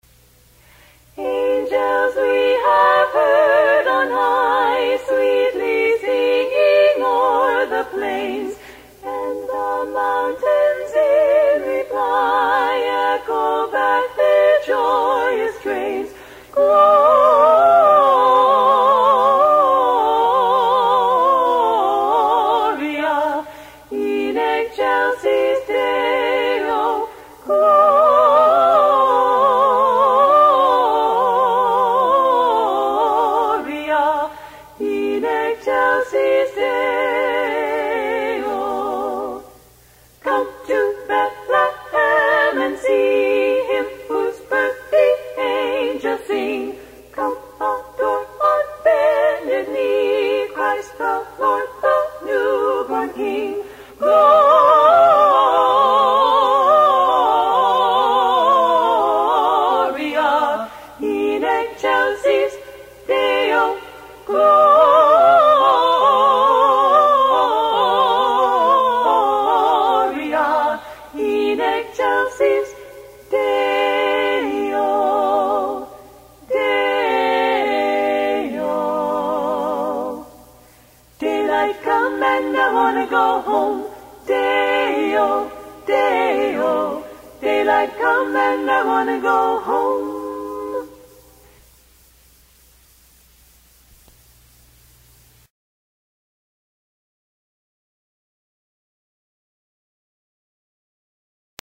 Christmas Carols, Christmas Songs, Christmas Music - - it won't be long before the "season to be jolly" is upon us!
Memorial Miller Children's Hospital Terrific harmonies and delightful Victorian costumes make these Los Angeles Carolers a feast for the ears and eyes.